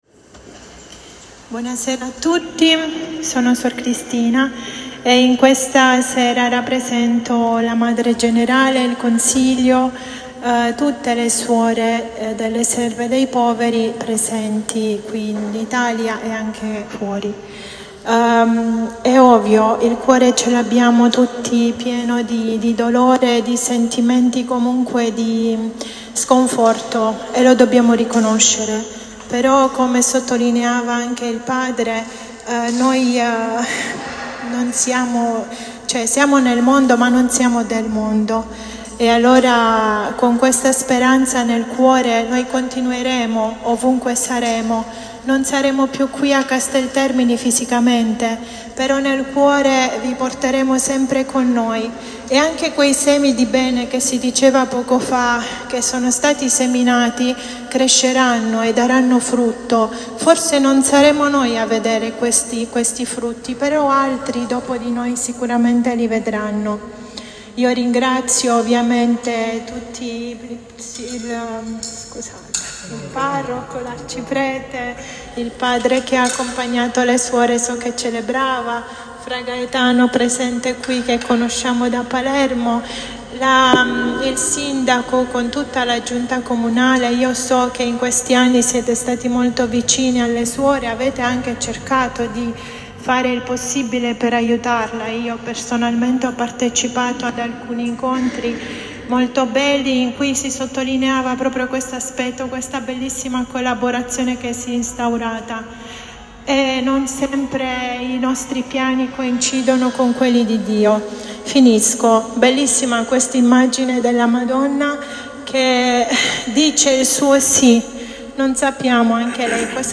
Alle ore 19,00, in chiesa Madre, è stata celebrata la S. Messa in ringraziamento al Signore per la presenza delle Suore da più di un secolo.
visibilmente commosso
a nome del personale